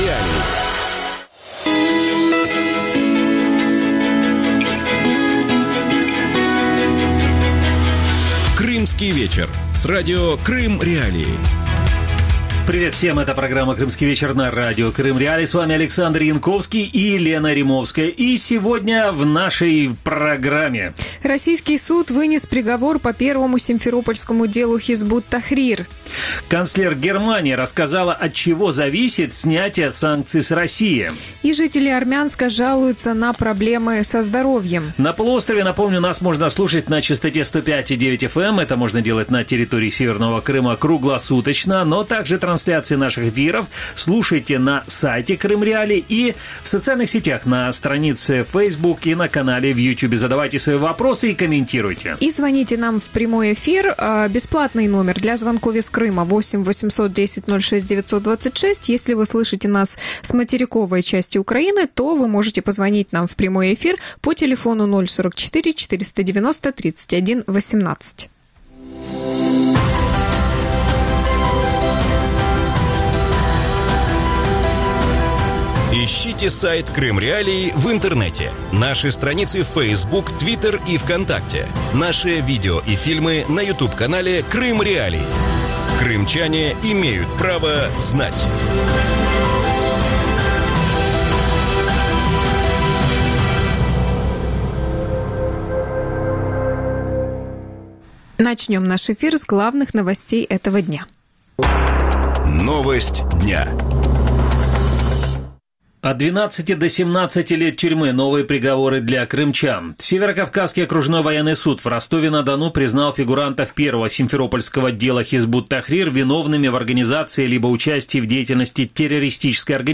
С чем связано значительное количество суррогатного топлива на крымских АЗС? Как качество топлива влияет на затраты крымских водителей и поломки их автомобилей? Могут ли автовладельцы самостоятельно определить качество топлива на крымских АЗС? Гости эфира: